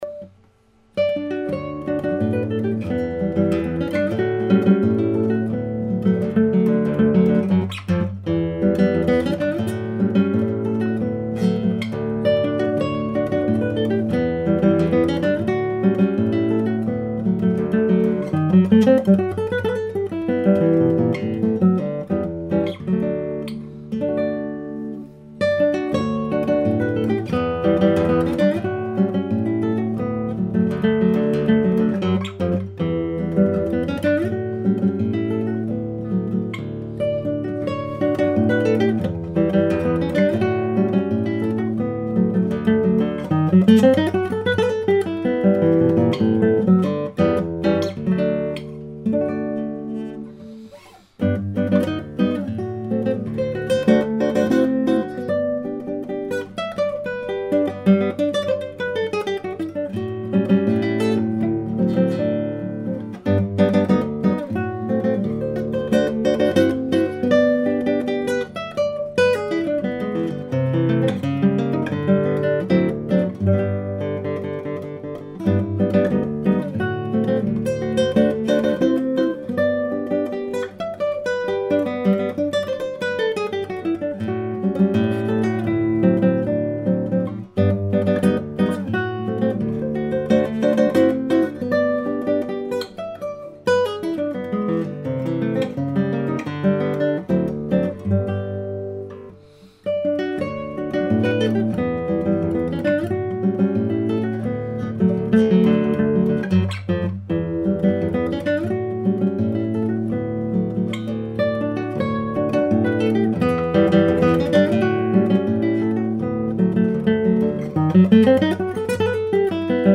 Mais je voudrais apporter mon grain de sel: je trouve que c'est trop vigoureux, trop ... trop impatient, et que ça manque de sentiments.
C'est brillant, dynamique, lumineux, et tu montres à    quel point tu maîtrises cet instrument épouvantable qu'est une guitare classique!!!